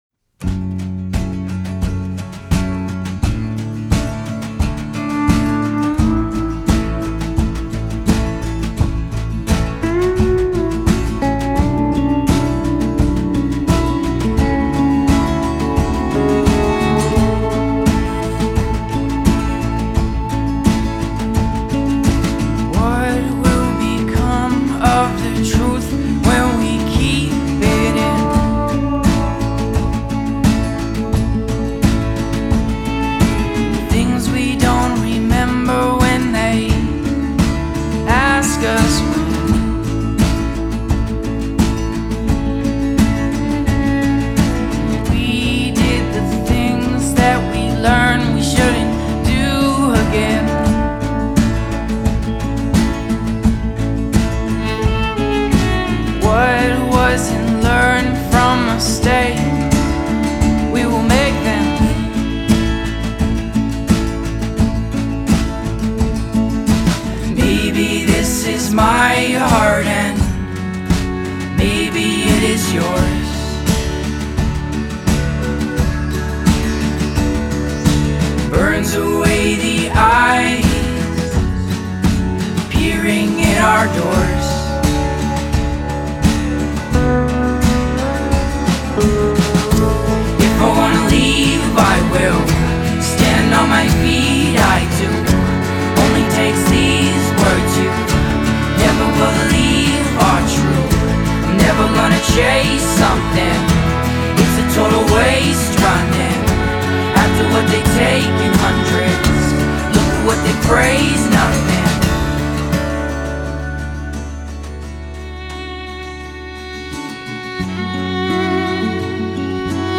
So pretty.
This is camping bonfire canned beer music.